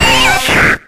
Audio / SE / Cries / WEEDLE.ogg